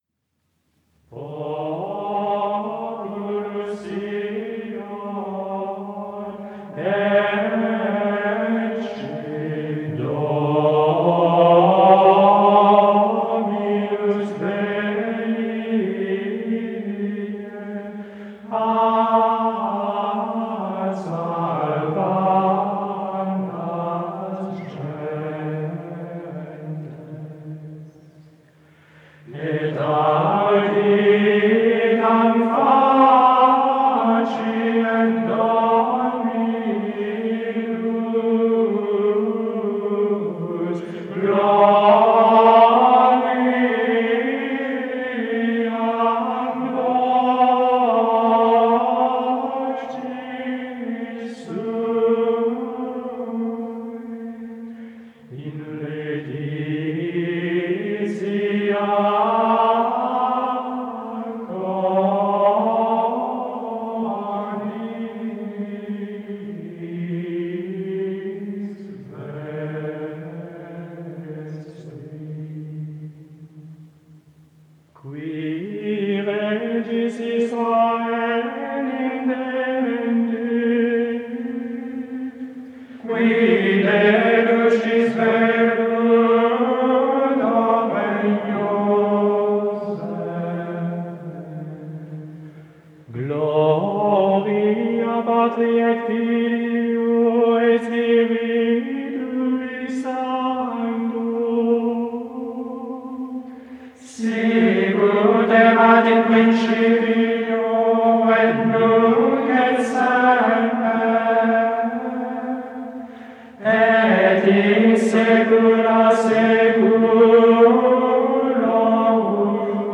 L’introït de la messe de ce dimanche, par les moines de Ligugé en 1956 :
L'effet de la quarte ascendante de l'introït d'aujourd'hui est si différent de celui de la quarte descendante de l'introït de dimanche dernier !
La vraie dominante des première et troisième phrases est do ; celle de la deuxième, ré.